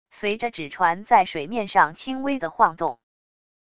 随着纸船在水面上轻微的晃动wav音频